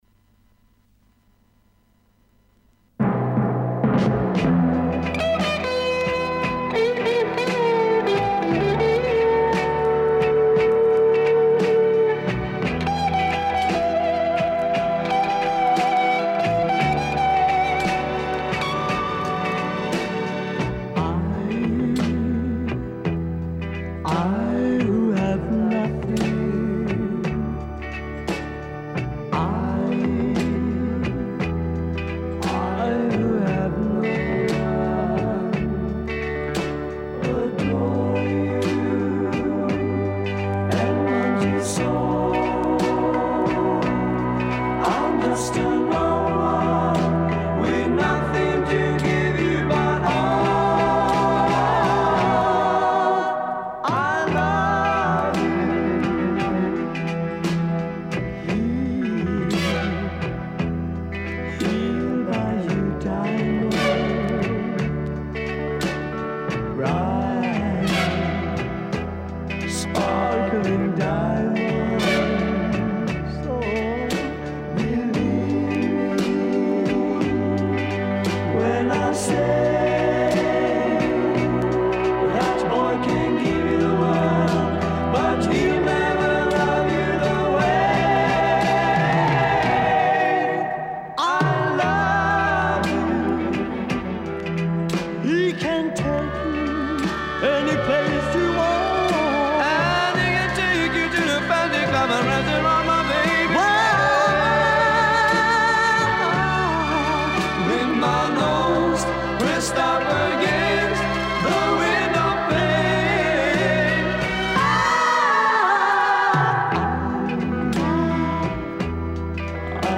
Это Шведская поп группа из далеких 60 годов...